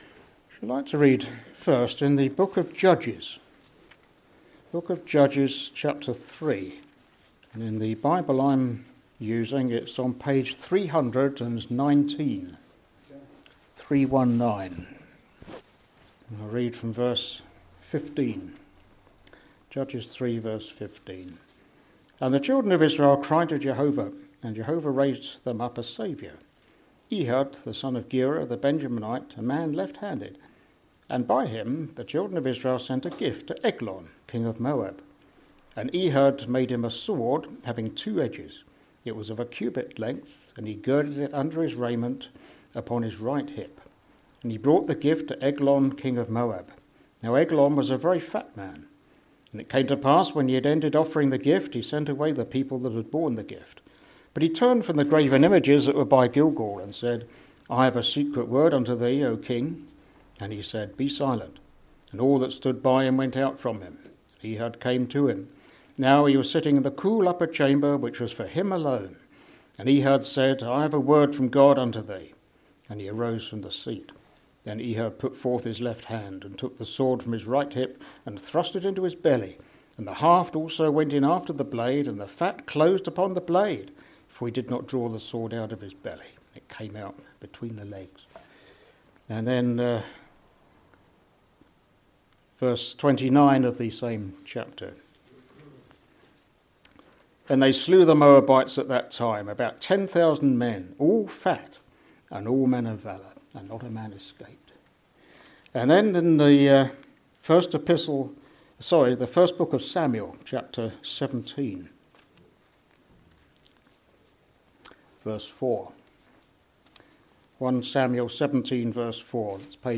In this Gospel preaching you will hear about the sword of life and the sword of death.